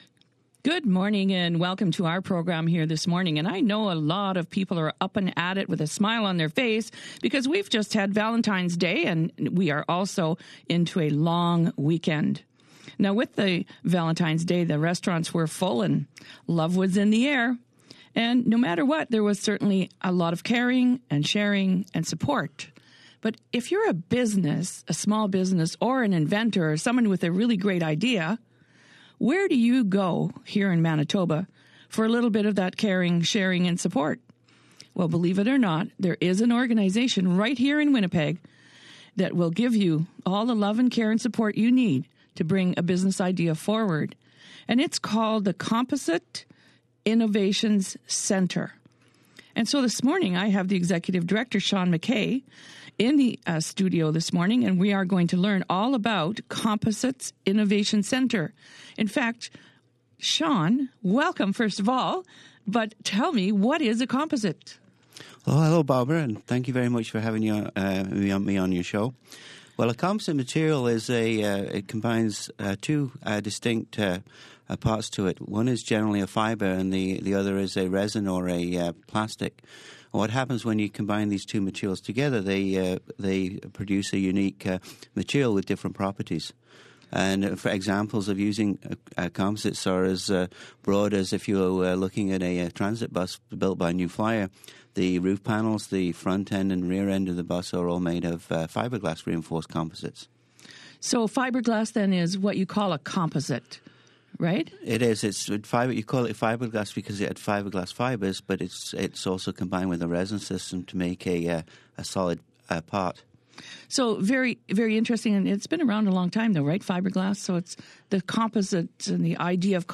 CIC radio interview on CJOB